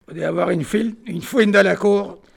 Localisation Olonne-sur-Mer
locutions vernaculaires